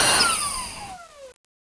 Index of /cstrike/sound/turret
tu_spindown.wav